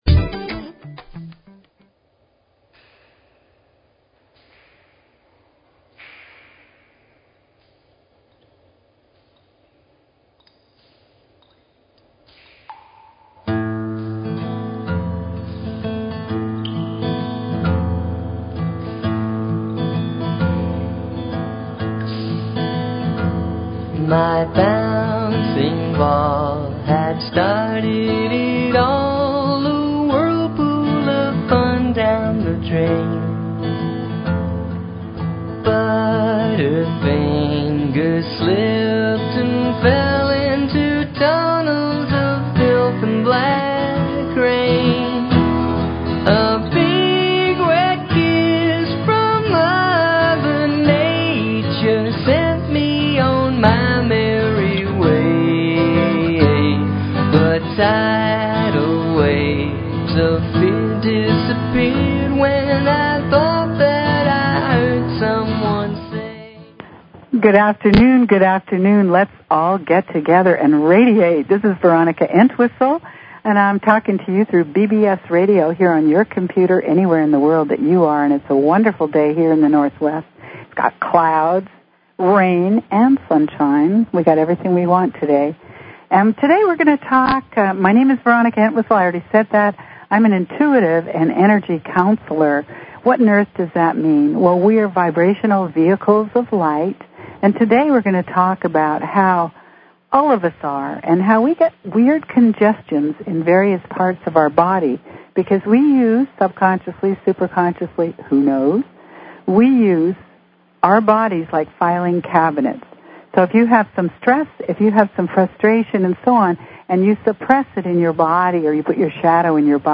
Talk Show Episode, Audio Podcast, Radiance_by_Design and Courtesy of BBS Radio on , show guests , about , categorized as
The Body As A Record Keeper-A call in show all about you. Call in and we will talk about your body and how, where and why you file your stresses and suppressions throughout your body/mind to create health problems...or where you store your gifts!